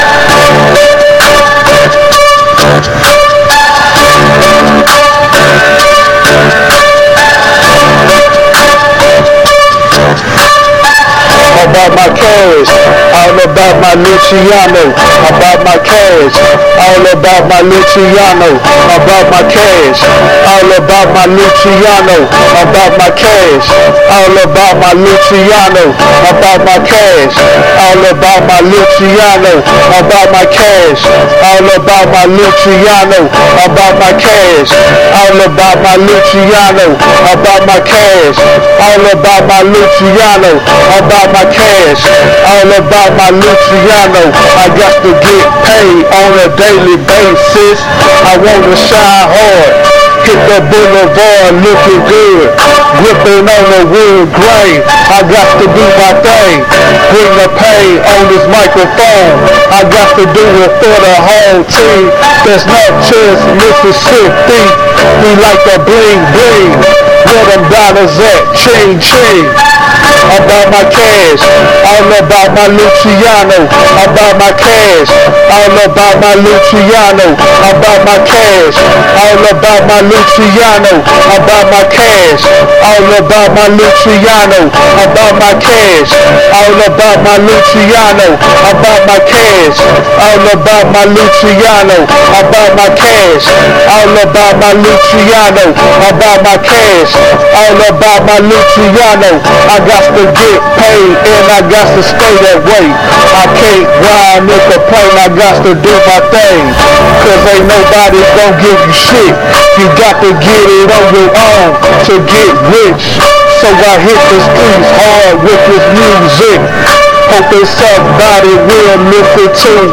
Hip-hop
Rhythm & Blues
Funk